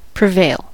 prevail: Wikimedia Commons US English Pronunciations
En-us-prevail.WAV